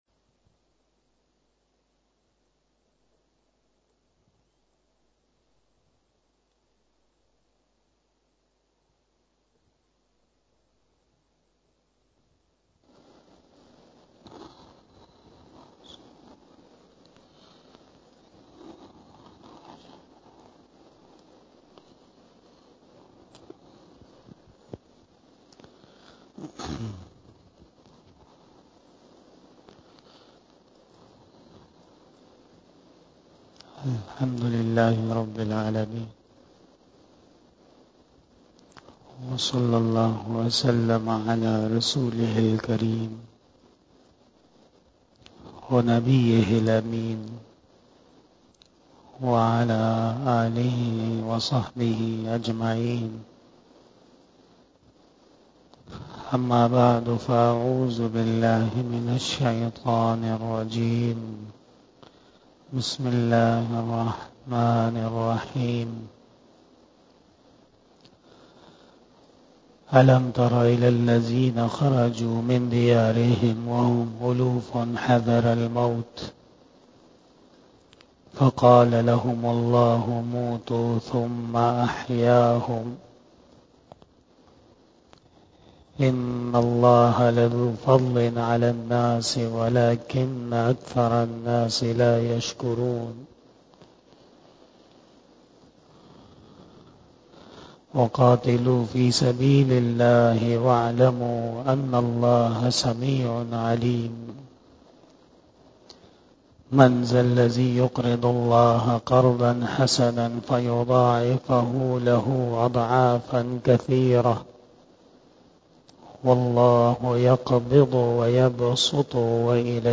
بیان شب جمعۃ المبارک
Bayan